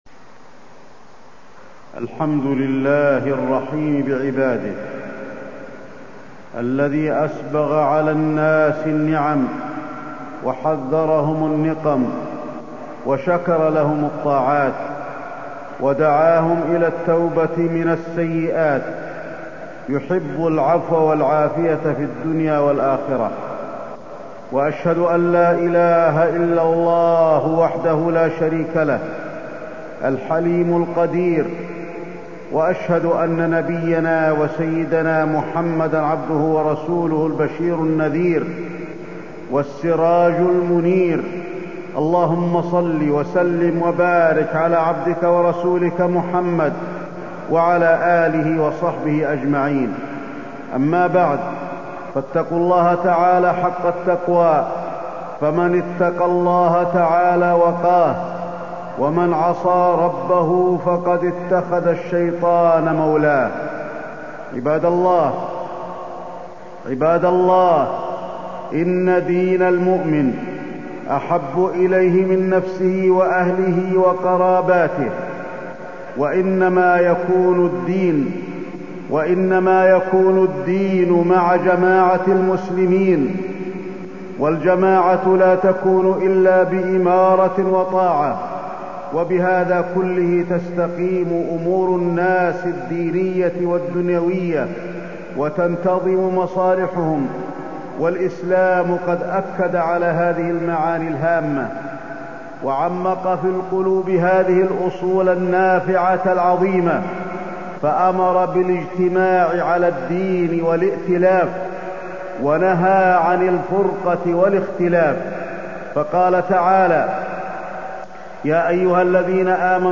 تاريخ النشر ١٤ جمادى الأولى ١٤٢٥ هـ المكان: المسجد النبوي الشيخ: فضيلة الشيخ د. علي بن عبدالرحمن الحذيفي فضيلة الشيخ د. علي بن عبدالرحمن الحذيفي الأحداث الأخيرة